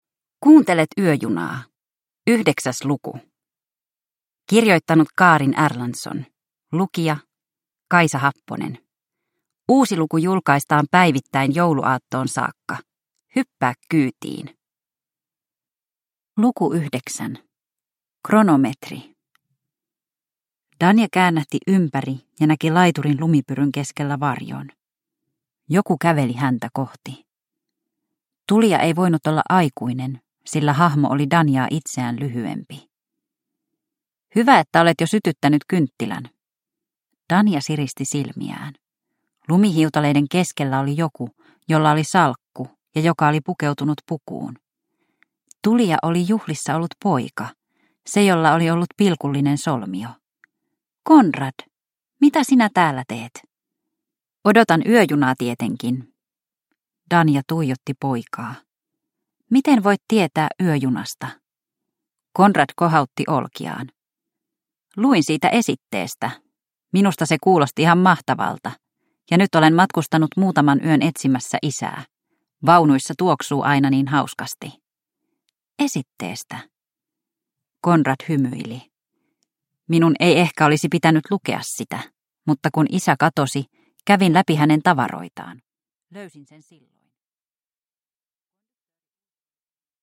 Yöjuna luku 9 – Ljudbok